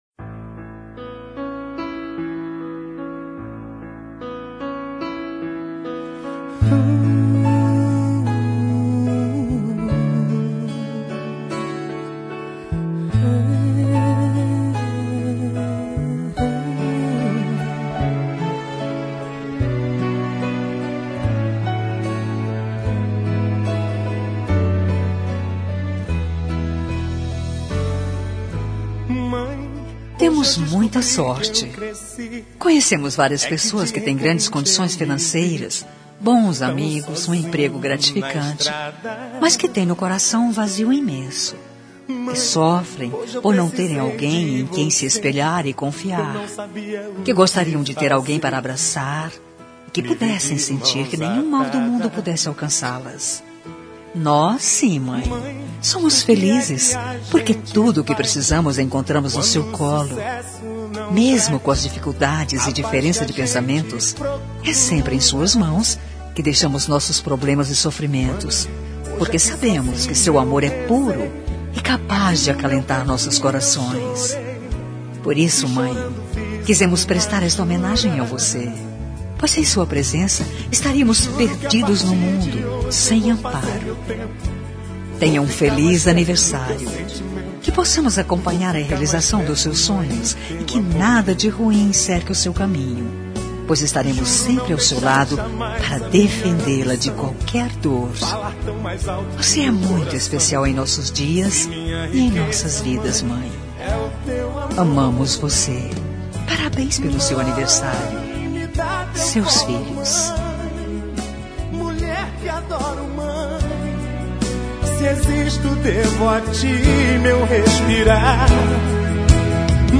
Telemensagem Aniversário de Mãe – Voz Feminina – Cód: 1414 – Rick e Rener